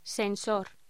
Locución: Sensor